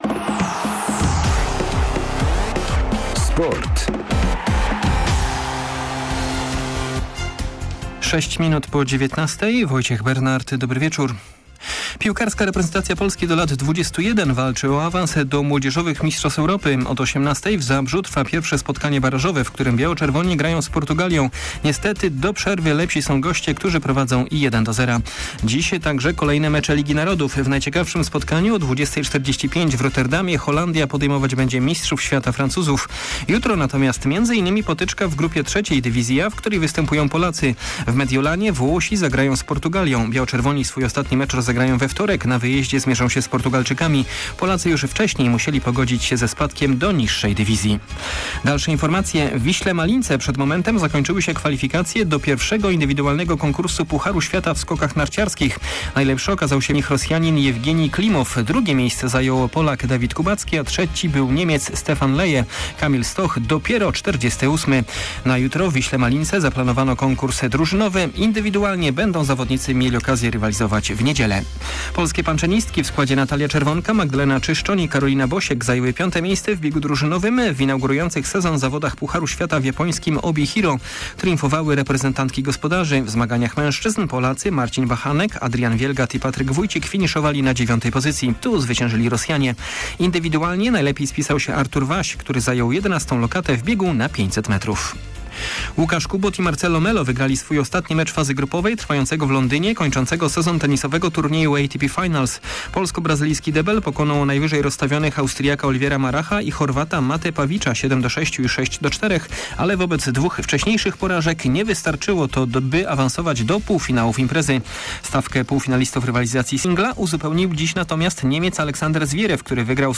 16.11. SERWIS SPORTOWY GODZ. 19:05